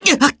Fluss-Vox_Damage_jp_01.wav